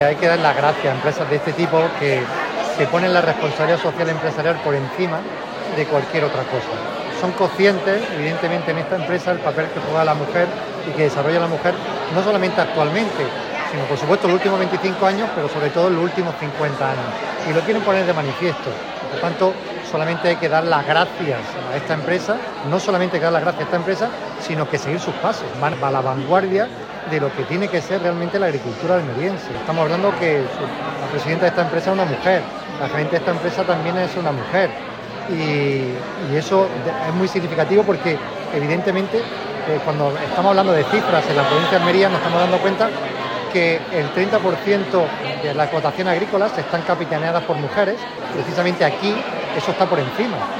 El presidente de la Diputación ha participado en la Jornada ‘El Papel de la Mujer en la Agricultura’ que ha organizado la empresa hortícola en sus instalacines de Níjar